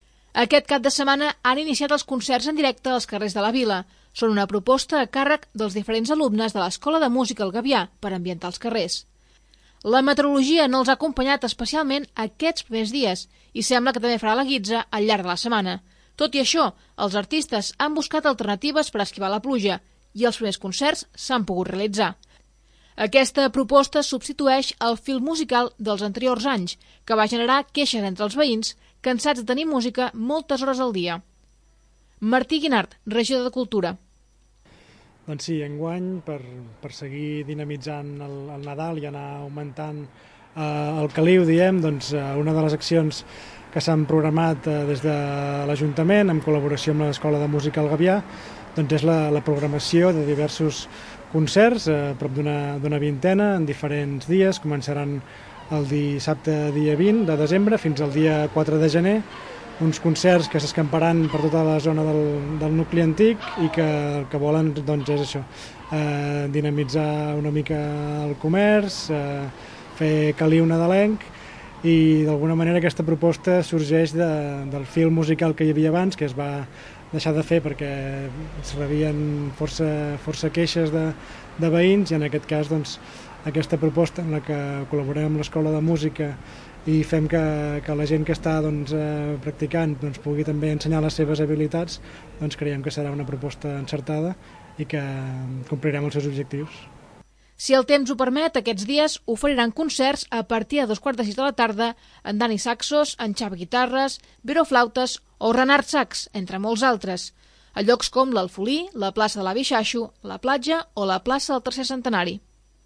Sota el títol 'El Nadal que jo conec', l'Escola de Música El Gavià ha celebrat el seu tradicional concert de Nadal a la Sala Polivalent.  Enguany amb una primera part de concert musical i la segona amb la cantata.
Els primers a pujar han estat el grup de guitarres, seguit dels violins, per acabar amb un clàssic a ritme de saxo com és All I Want for Christmas Is You.
Un concert que finalitzava amb un vis i on els pares també participaven a ritme de campanades de Cap d'Any.